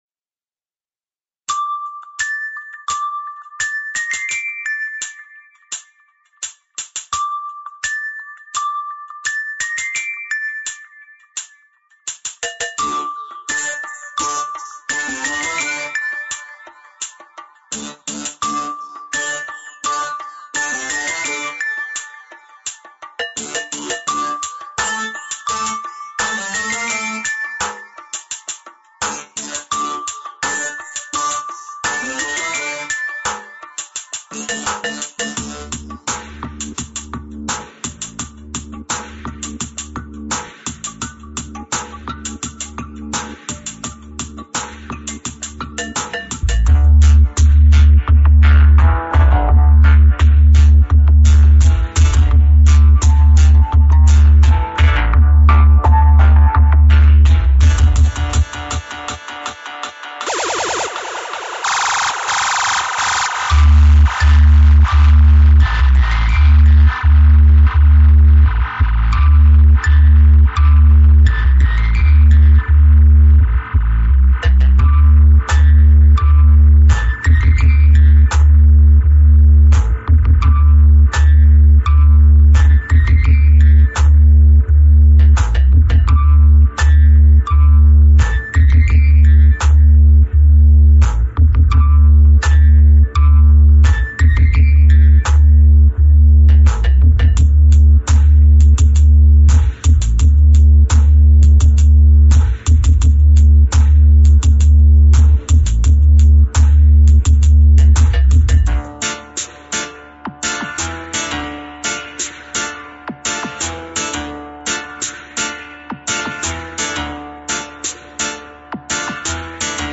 Soundtape style.